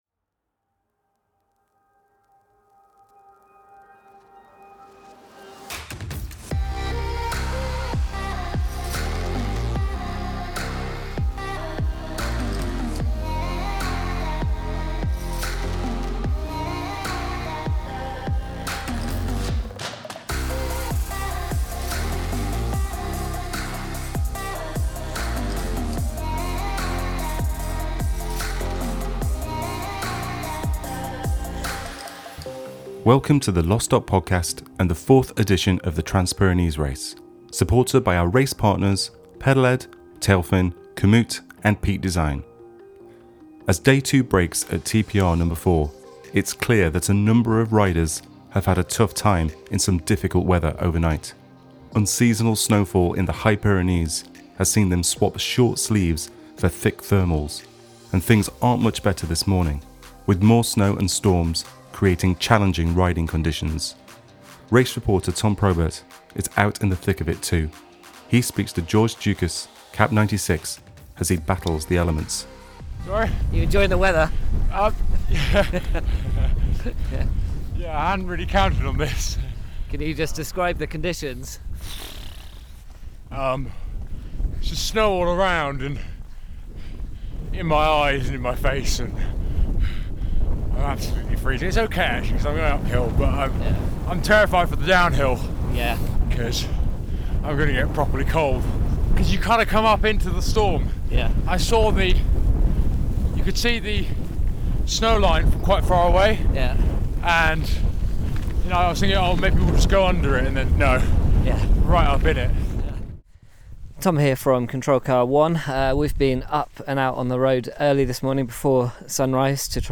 Cold temperatures, snow, rain, and ice test riders and their chosen kit. Hear from the various riders scattered across the Pyrenees as they climb and descend Port de la Bonaigua, the Bujaruelo Valley and the famous Peyresourde, Aspin, and Tourmalet. Race Reporters find riders indoors too, warming up in cafe’s and at resupply points, getting the feeling back into their extremities.